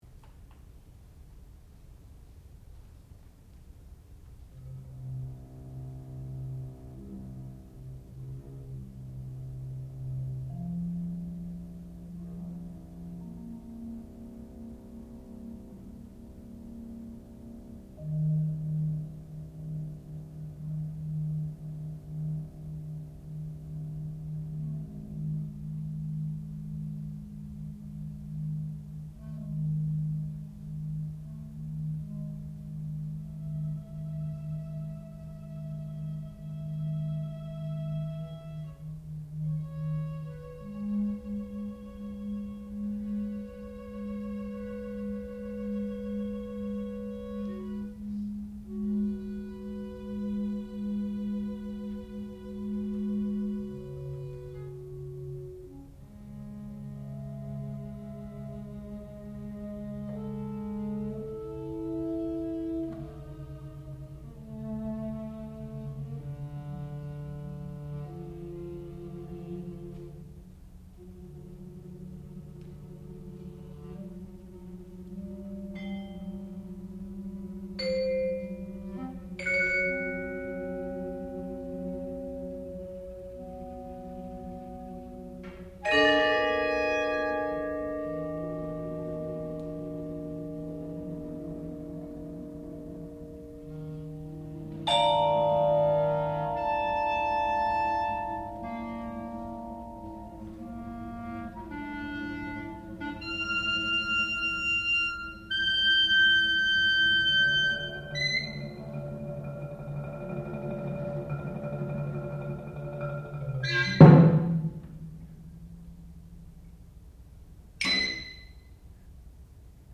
sound recording-musical
classical music
violoncello
clarinet
percussion